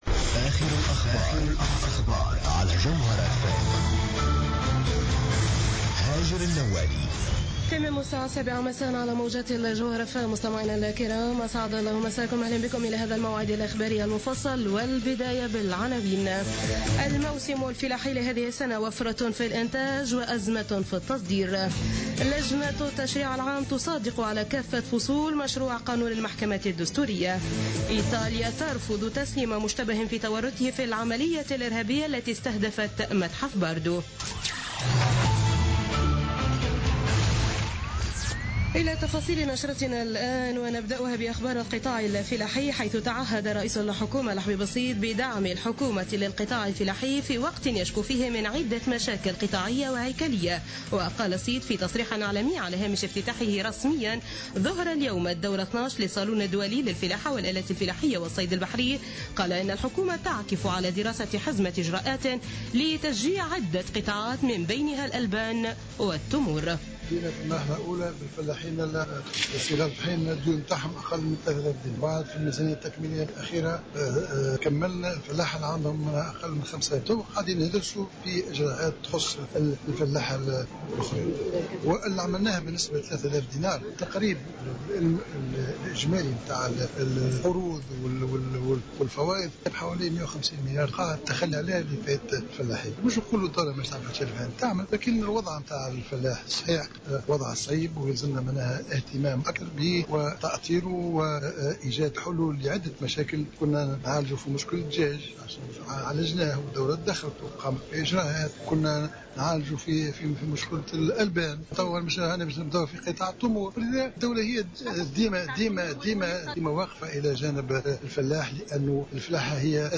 نشرة أخبار السابعة مساء ليوم الأربعاء 28 أكتوبر 2015